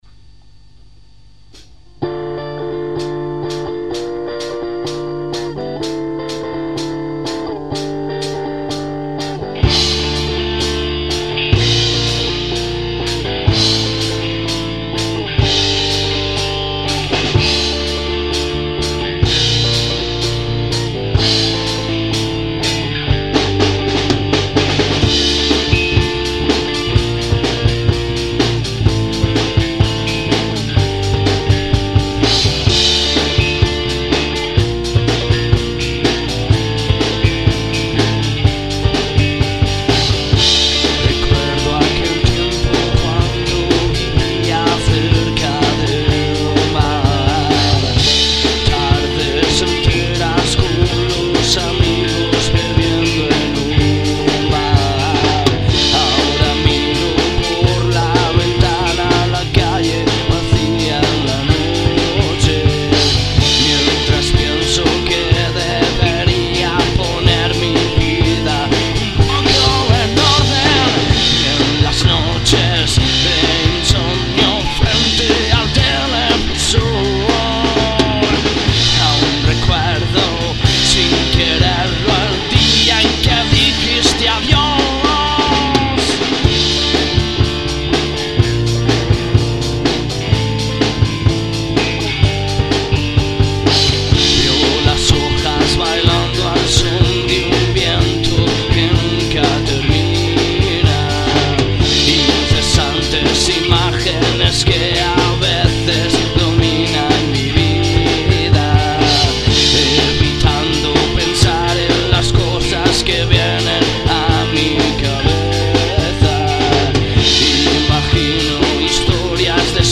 rock
Voz
Guitarra y coros
Batería
Demo Songs